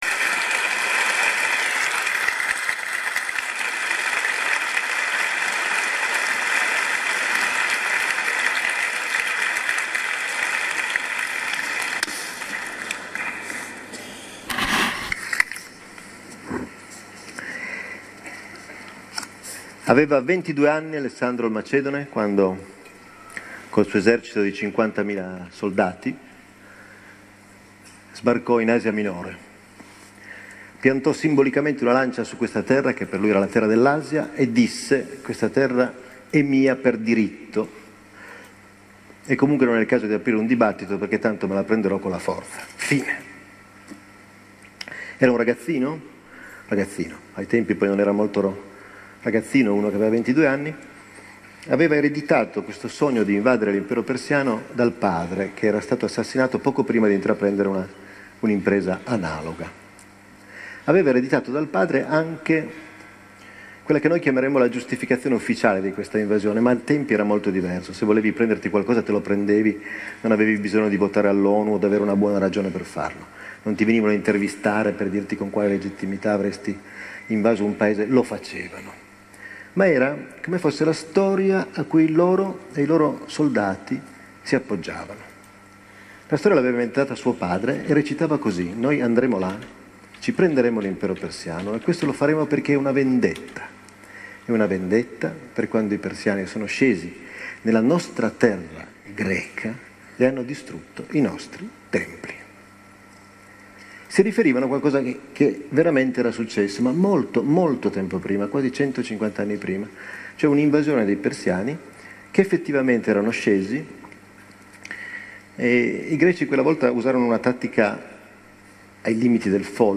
ALESSANDRO BARICCO, Alessandro Magno. SULLA NARRAZIONE, registrazione AUDIO a Mantova Lectures, 2016